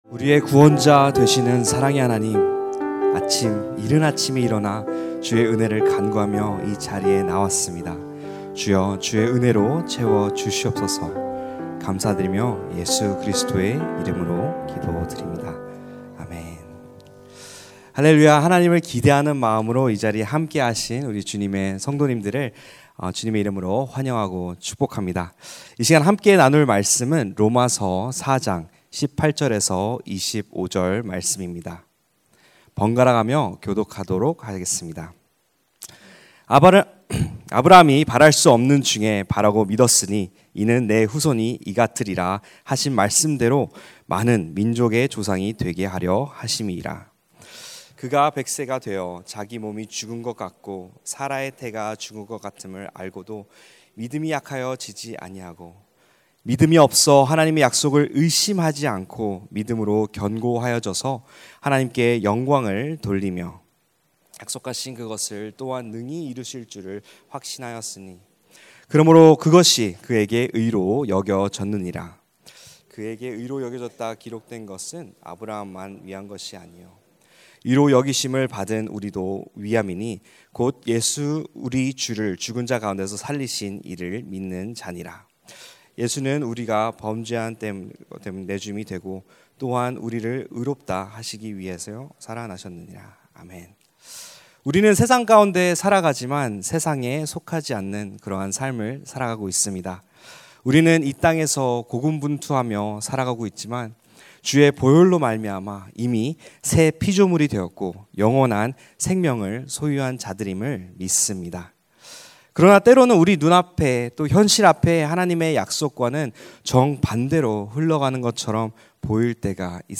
> 설교
[새벽예배]